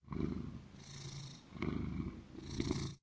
purr1.ogg